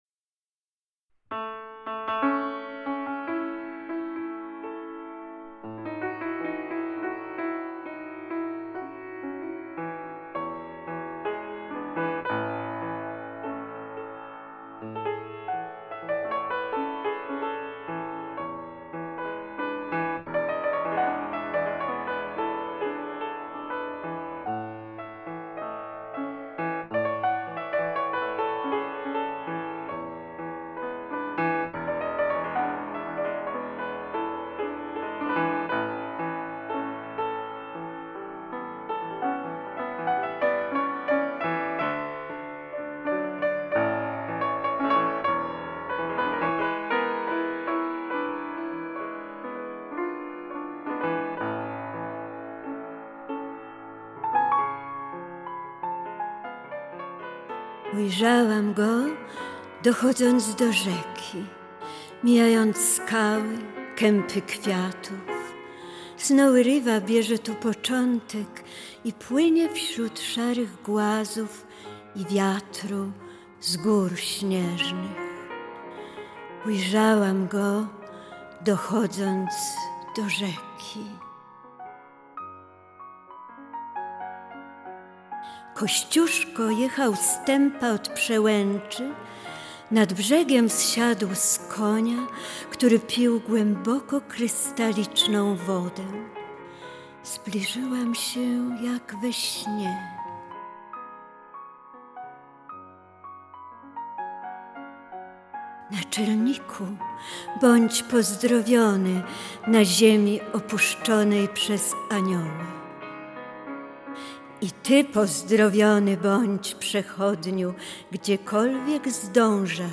melorecytacja
Kościuszko nad Snowy River, recytuje